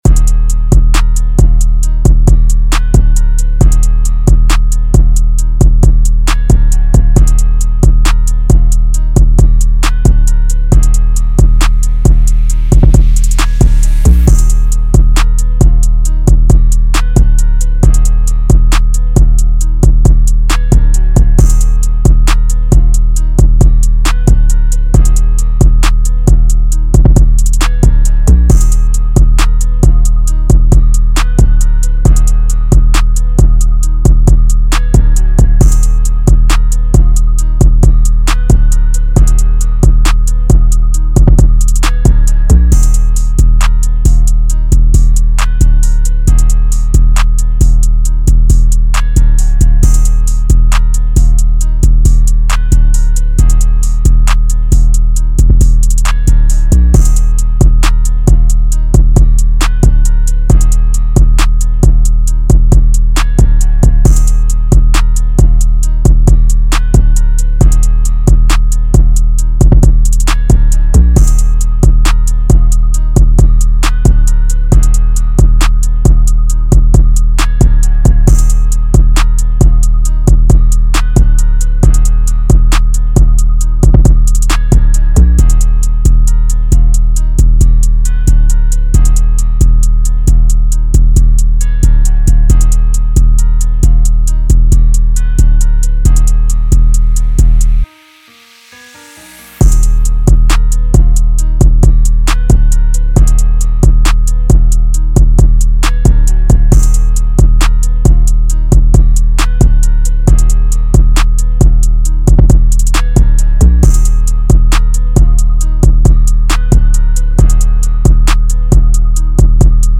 Trap Instrumentals